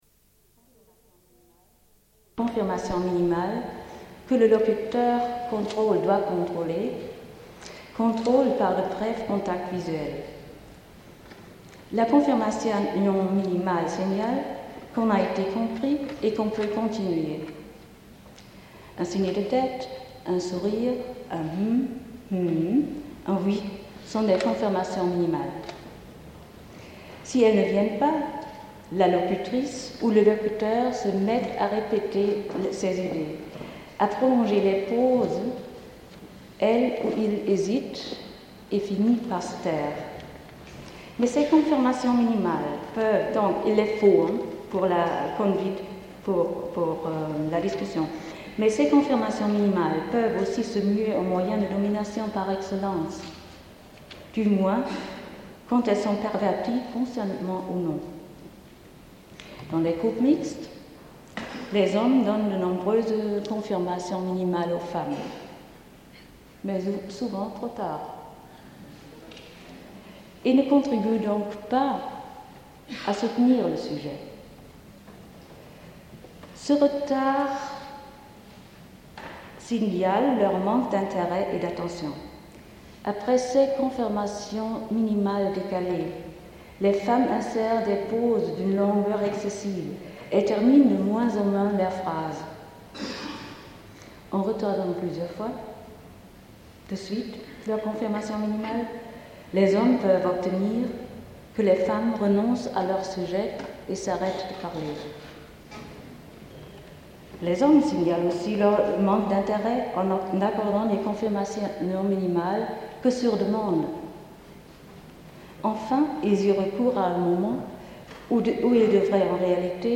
Une cassette audio, face A19:53